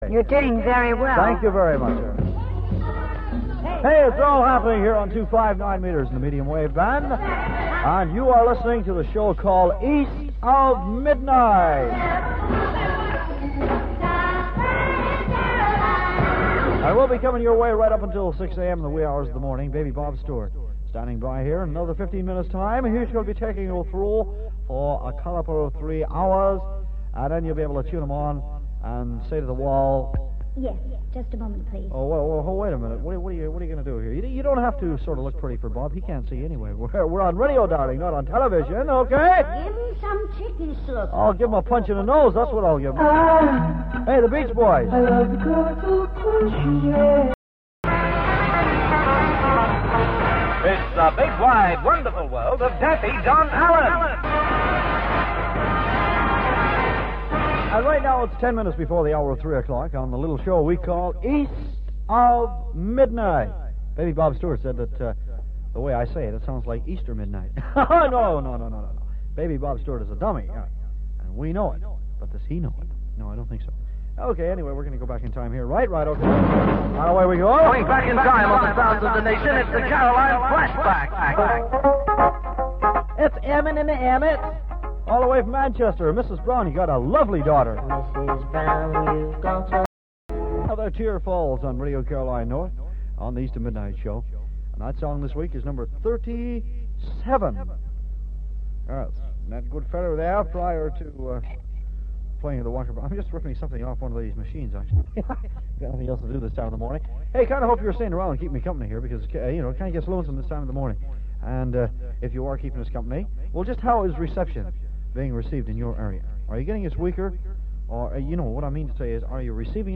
‘East of Midnight’ test transmission on 259m